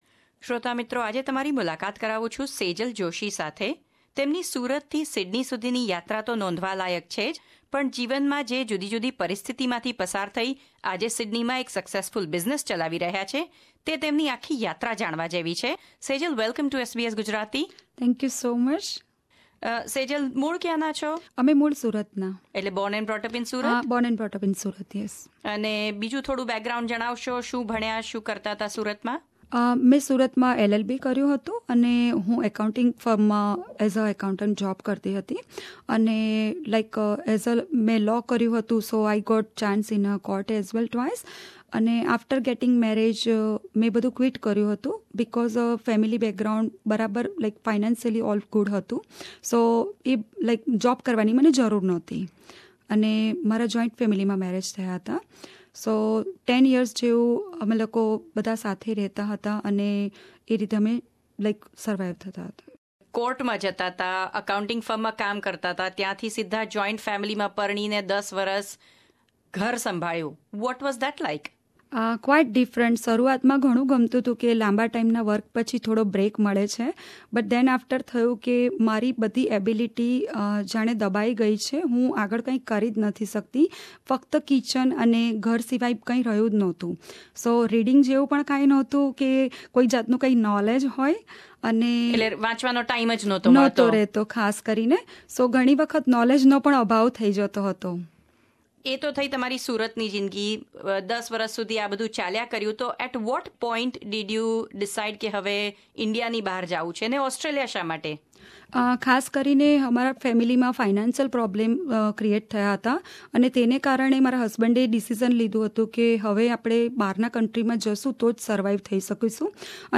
at SBS studio in Sydney